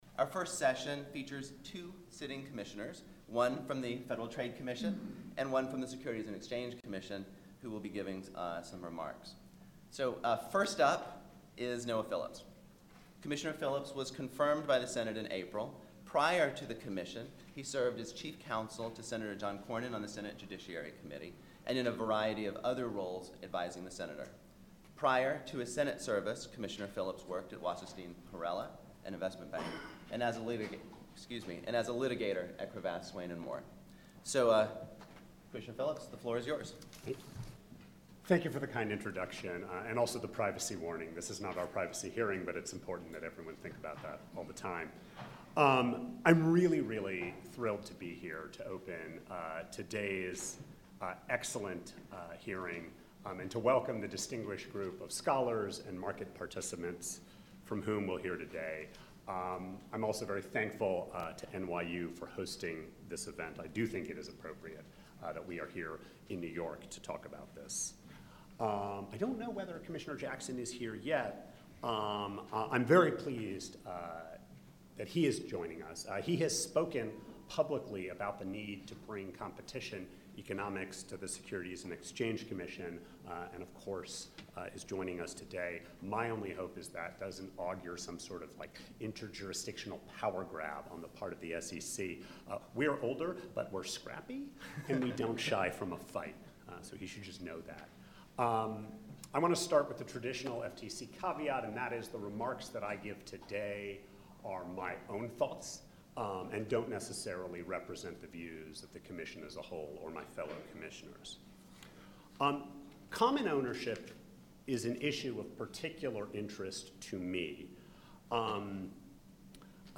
This is an audio recording of the opening remarks and discussion with FTC Cmr. Noah Joshua Phillips and SEC Cmr. Robert J. Jackson Jr. at the eighth Hearing on Competition and Consumer Protection in the 21st Century held at the New York University School of Law in New York City on December 6, 2018. The theme of the hearing was "Common Ownership."